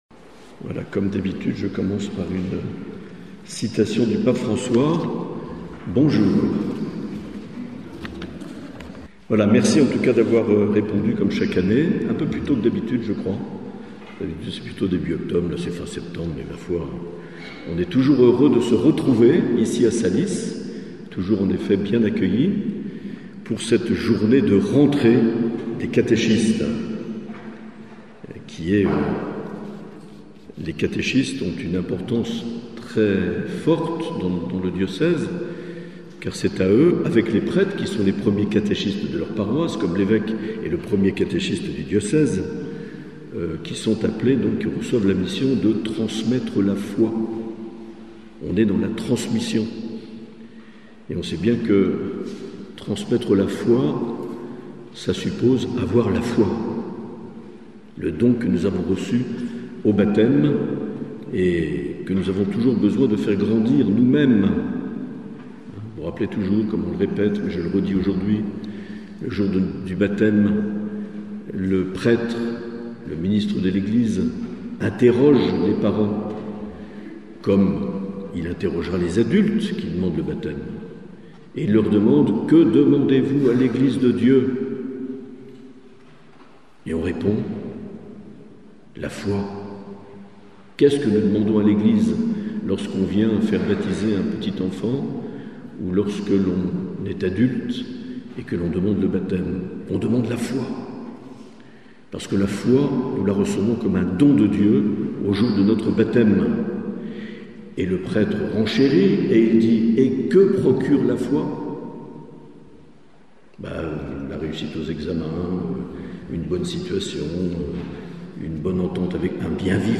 Journée des catéchistes à Salies-de-Béarn le 24 septembre 2024
Mgr Marc Aillet a donné une conférence pour expliquer les enjeux de l’Année sainte qui se déroulera dans le monde entier du 25 décembre 2024 au 28 décembre 2025. Le but de cette Année jubilaire : nous faire grandir en sainteté pour annoncer l’Évangile.